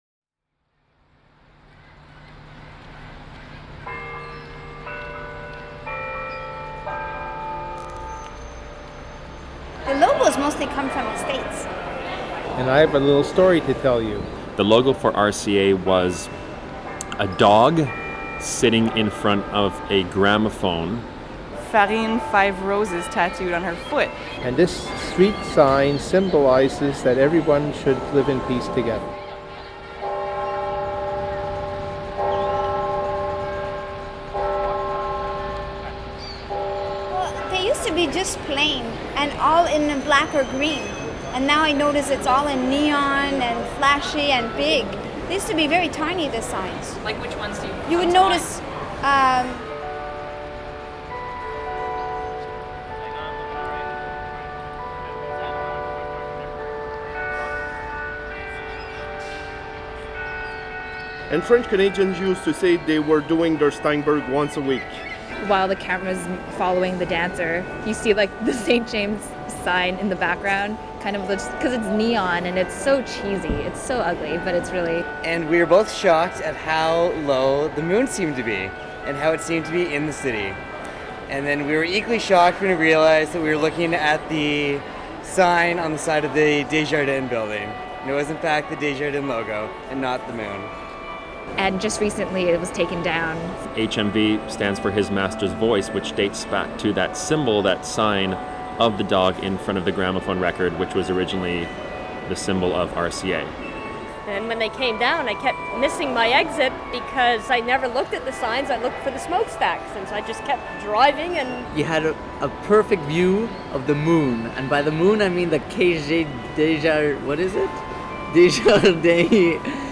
The background is one of my favorite downtown sounds: the Expo ’67 chimes that are now housed in the Sun Life Building on Place du Canada.
The voices are Montrealers telling stories about signs and logos around the city.